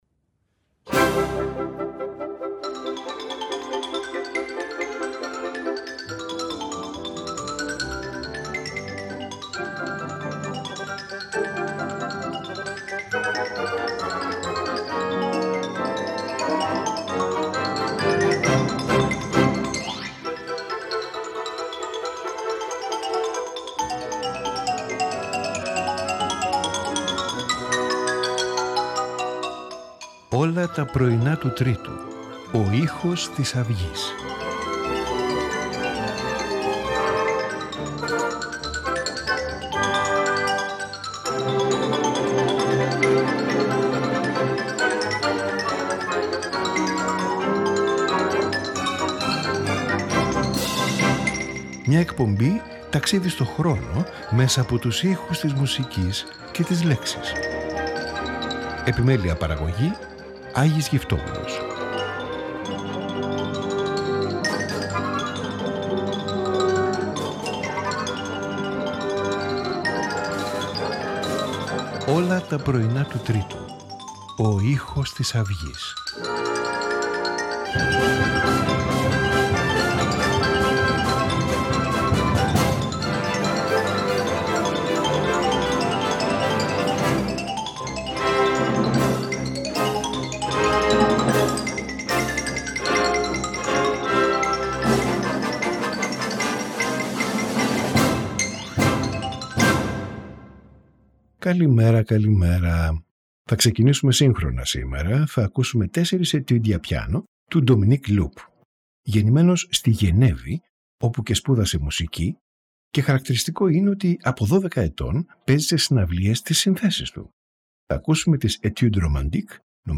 Piano Quintet in F minor
Flute Quintet No. 2 in G
Orchestral Suite (Overture) No. 3 in D